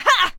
CosmicRageSounds / ogg / general / combat / creatures / alien / she / attack1.ogg
attack1.ogg